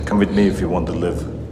One of the most iconic Arnold Schwarzenegger quotes.
Movie: Terminator 2: The judgment day